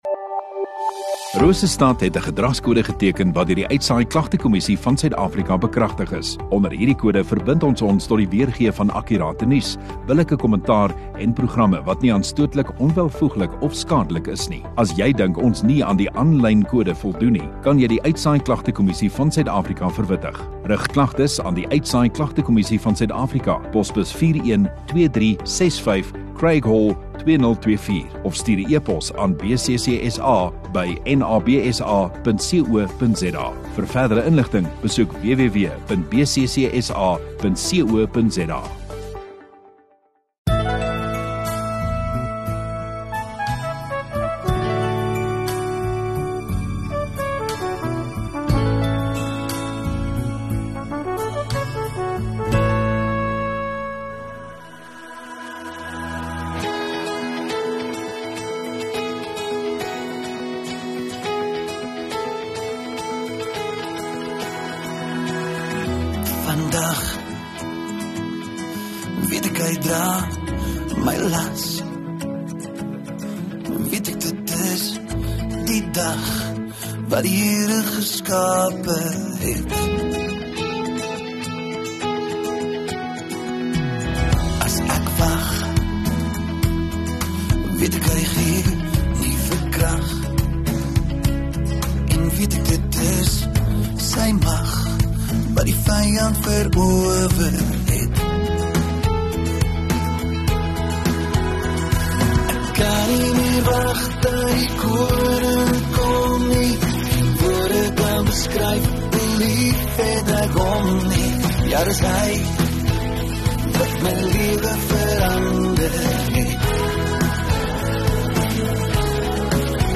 23 Nov Sondagaand Erediens